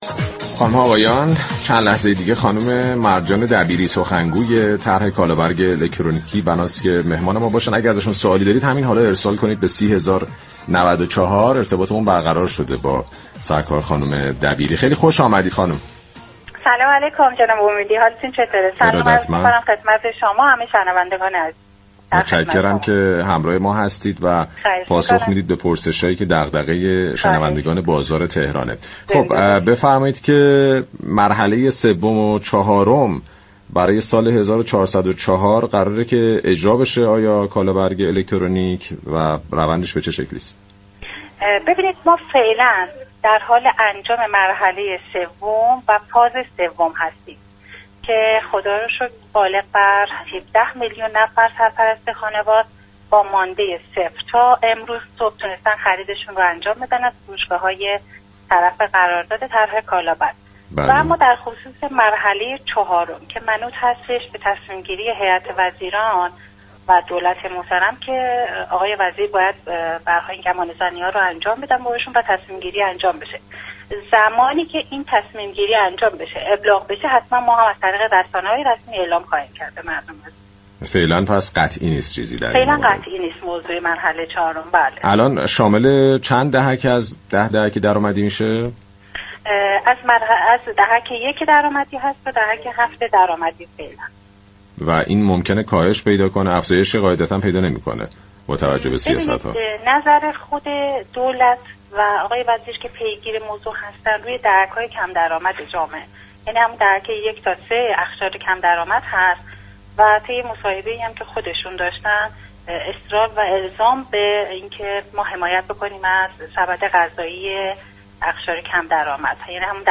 در پایان این گفتگوی رادیویی